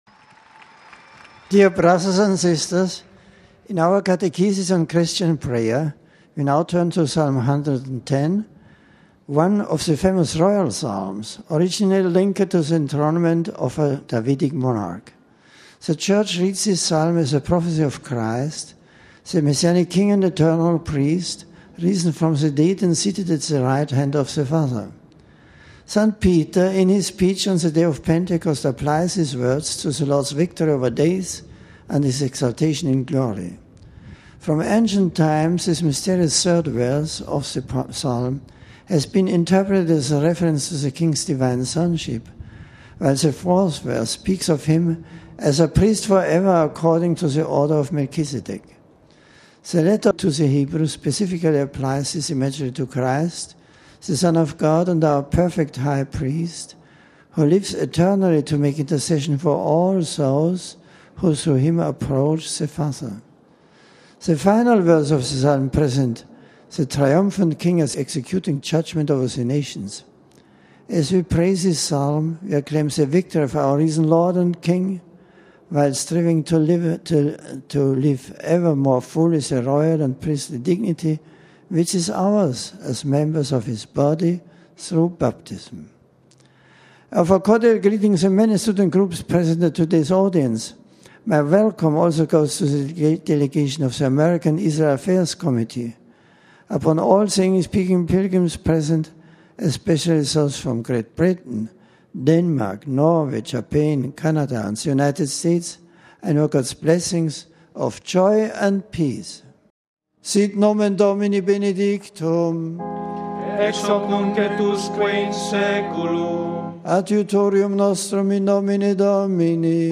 The general audience of Nov. 16 was held in the open in St. Peter’s Square.
An aide greeted the Pope on behalf of the English speaking pilgrims introducing the various groups to him. Pope Benedict then delivered a discourse in English: